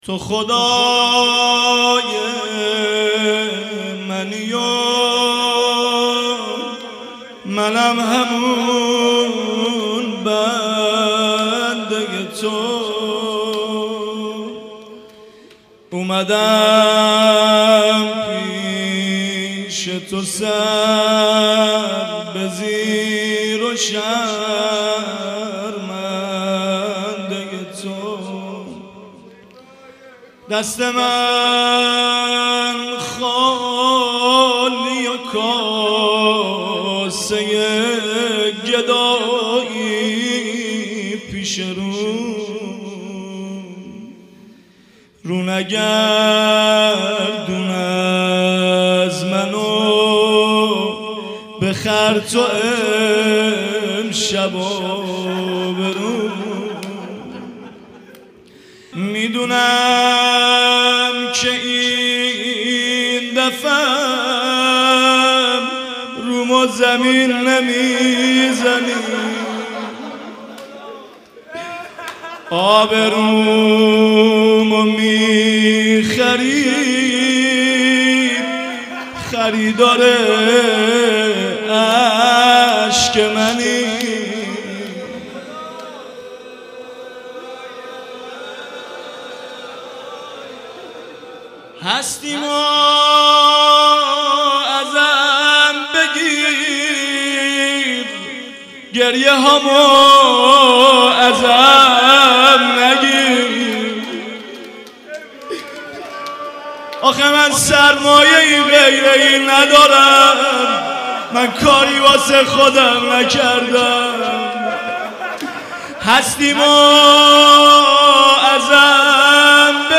مناجات زیبا/میدونم اینبار رومو زمین نمی زنی